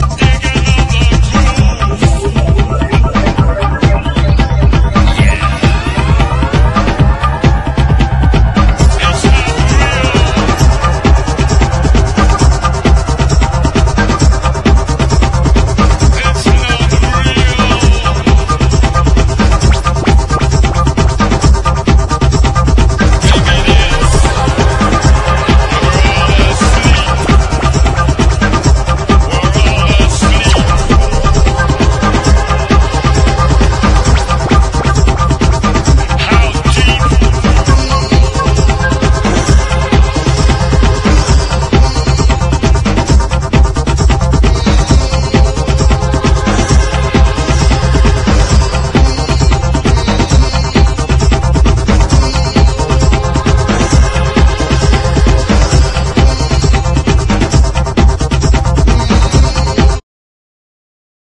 スモーキーでバレアリックなダブ・センスが映える！ハイブリッドなグラウンドビート・トラック！